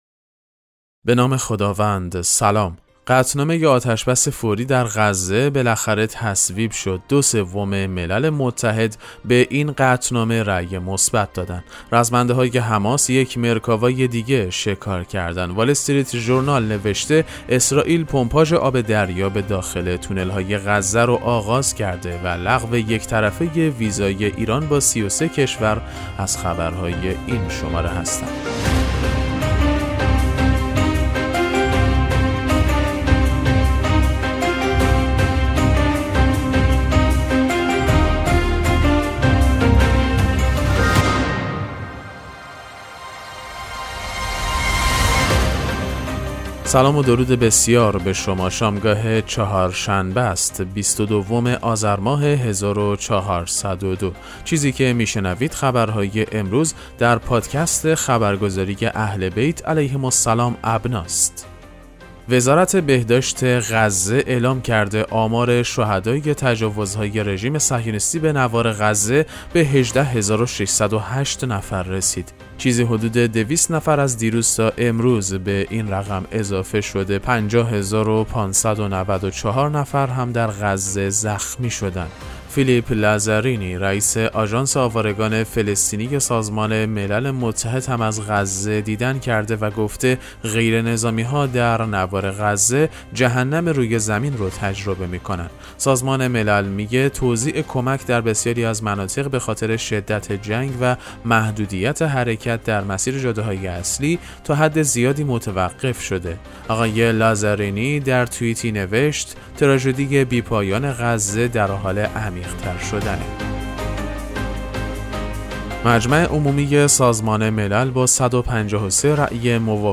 پادکست مهم‌ترین اخبار ابنا فارسی ــ 22 آذر 1402